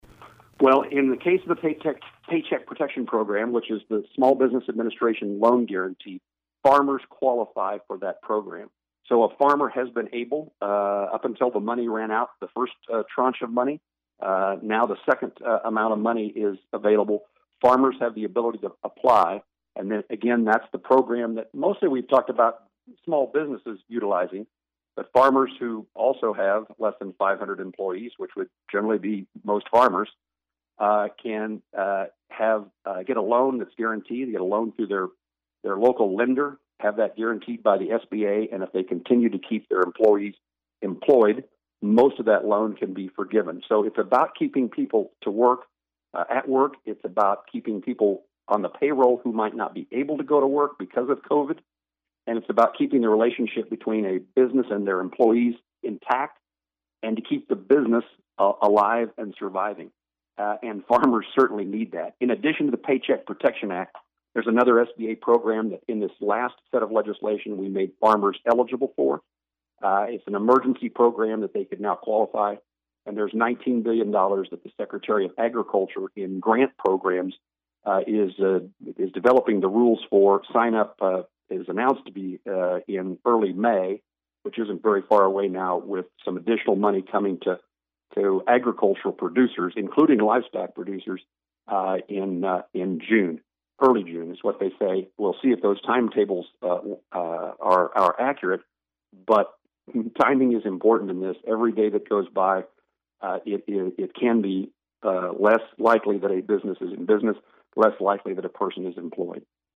COVID-19 Q & A with U.S. Senator Jerry Moran